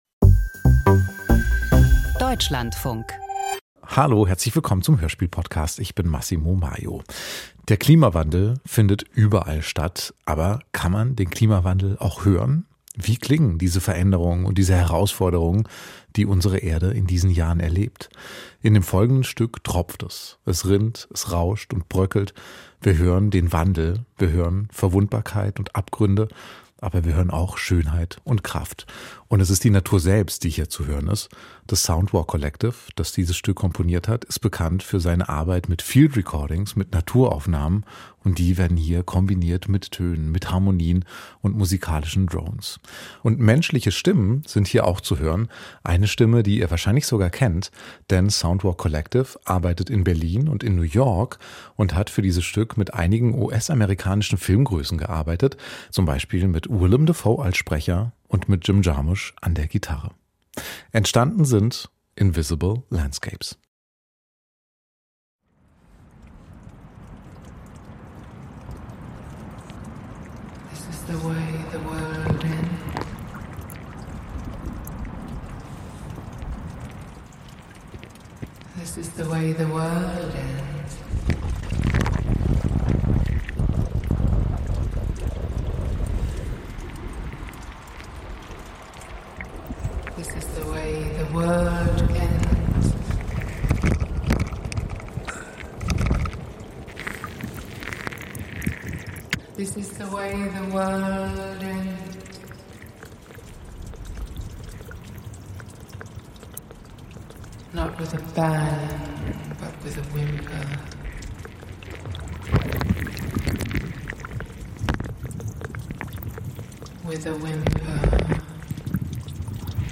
Klangkunst mit Willem Dafoe und Jim Jarmusch - Invisible Landscapes
Das Soundwalk Collective macht diese Entwicklung hörbar.